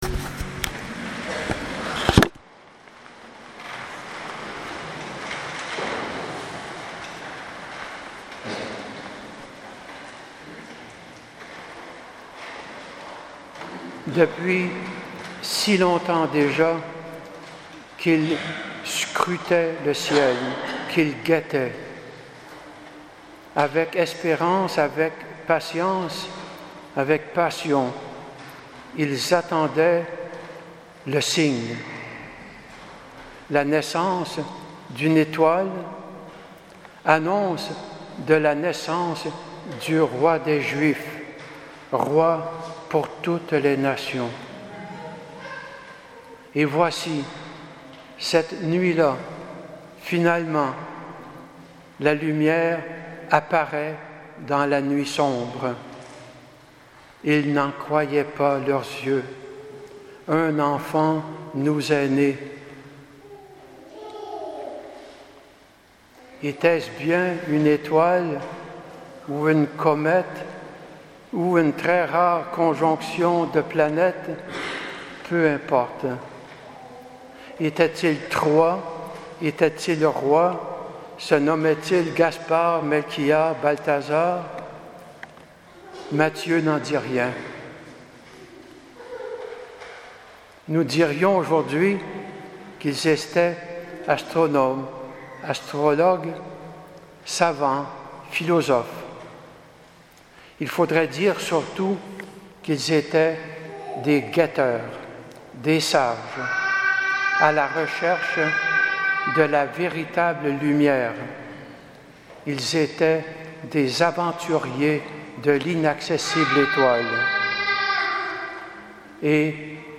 Homélie de la Solennité de l’Epiphanie 2018 (8 janvier)